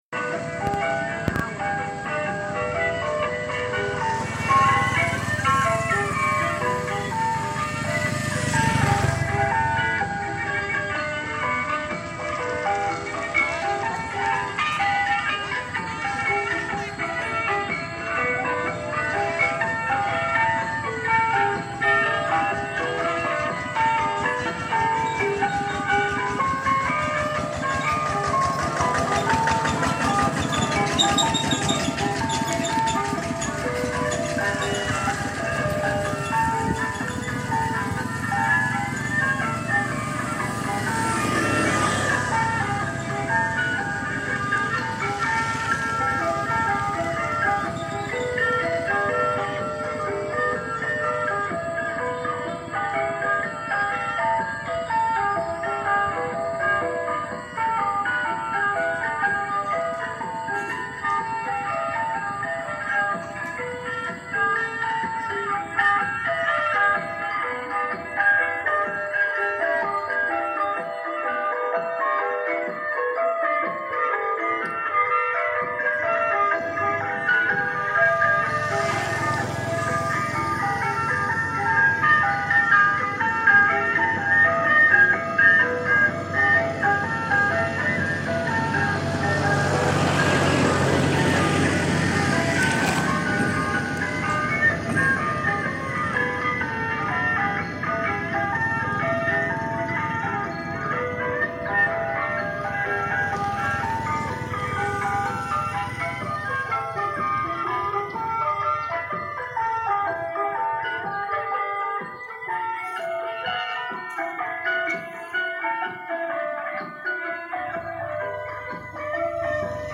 Sounds of a Buddhist temple, Cambodia
Early morning in the village of Banteay Chhmar, western Cambodia. The gentle tinkling sounds of the Buddhist pagoda (temple) were drifting across ancient ruins mixing with traffic sounds.